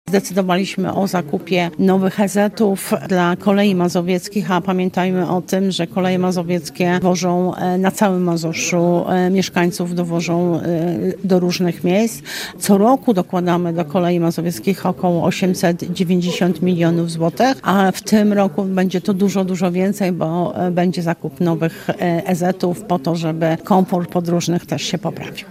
To pojazdy o bardzo wysokich standardach – mówi członkini zarządu województwa mazowieckiego, Janina Ewa Orzełowska: